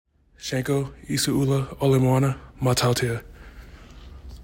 Pronunciation: shan COE EE say MAH TAH oo TEE ya